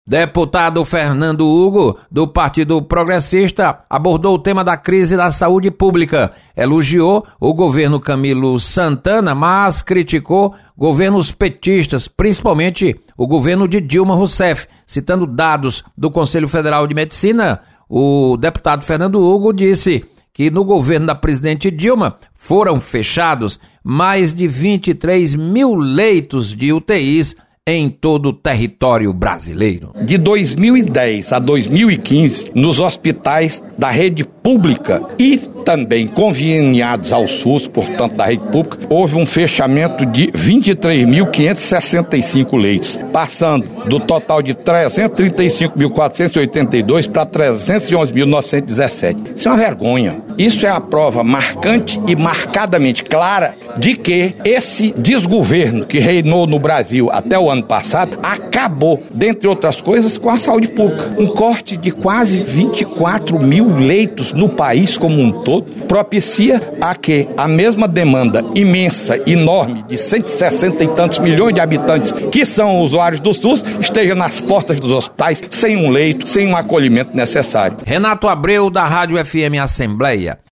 Deputado Fernando Hugo apresenta dados sobre leitos fechados do SUS. Repórter